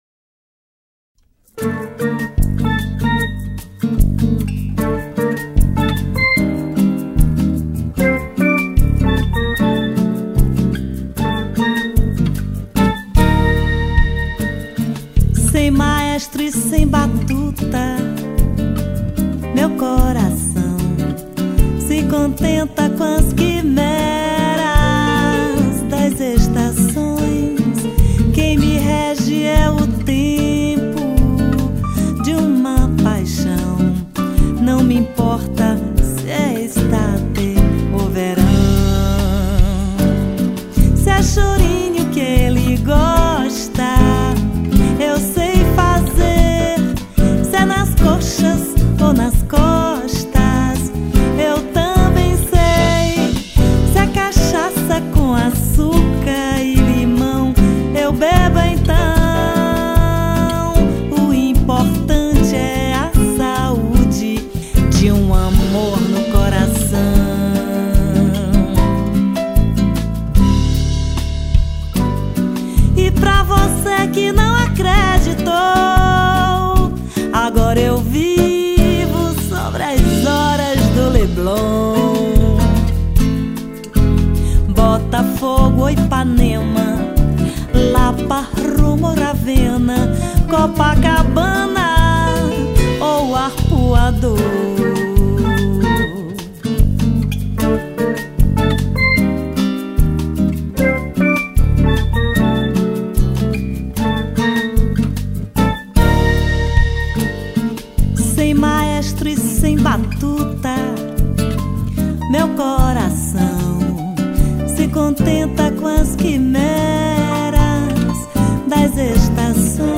1544   03:07:00   Faixa:     Bossa nova
Baixo Elétrico 6
Guitarra, Violao Acústico 6
Piano Acústico, Teclados
Bateria, Percussão